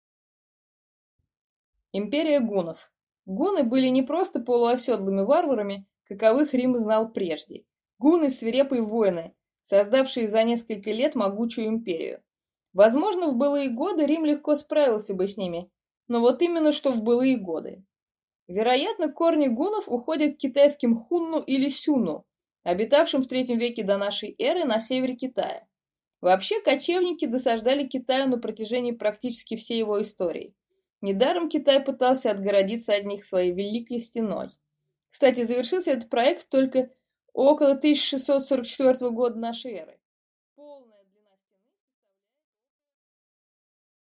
Аудиокнига Гунны и конец Рима | Библиотека аудиокниг